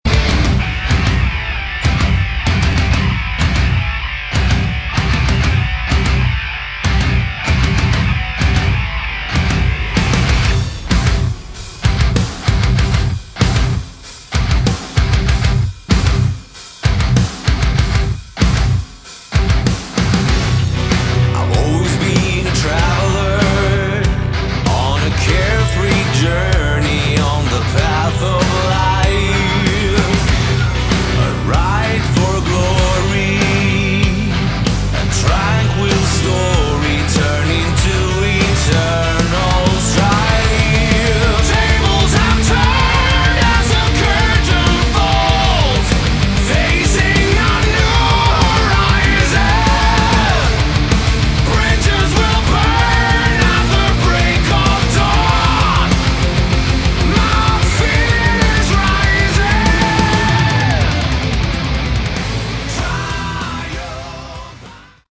Category: Melodic Metal
guitars, bass, drums
vocals